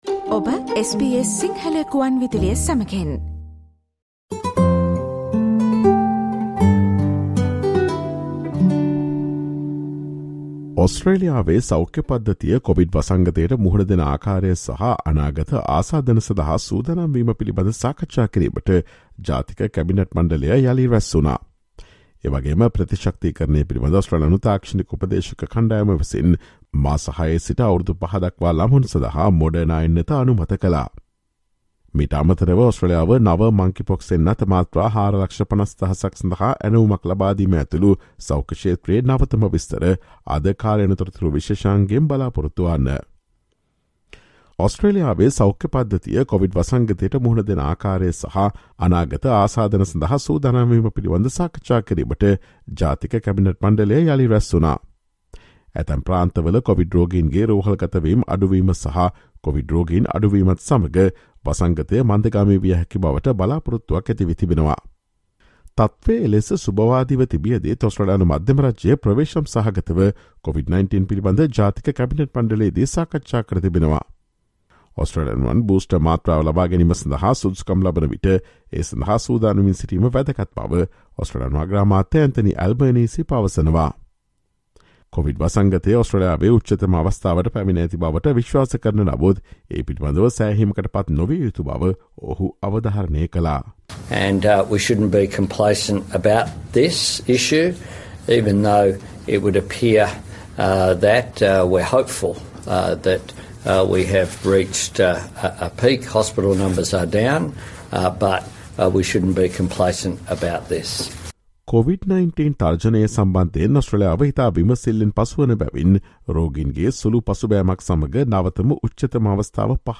Listen to the SBS Sinhala Radio's current affairs feature broadcast on Friday, August 5, with the latest information on the COVID-19 and the monkeypox threat in Australia.